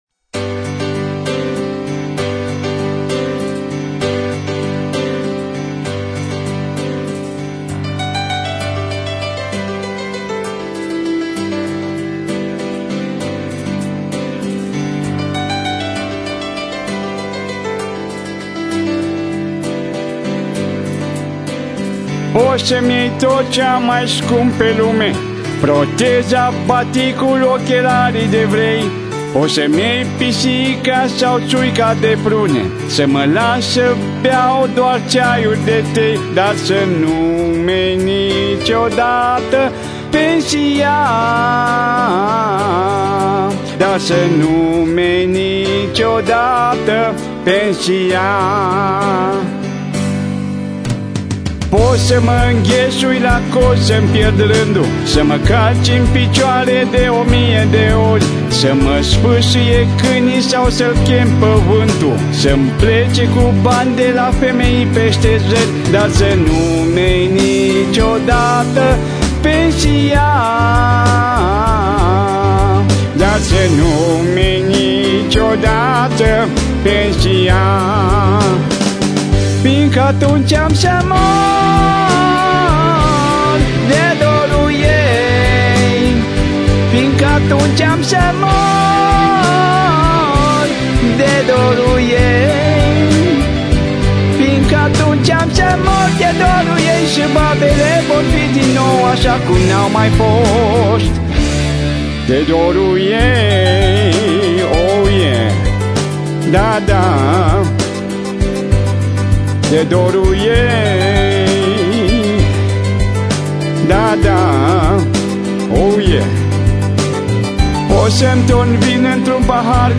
Trupa vocal-instrumentala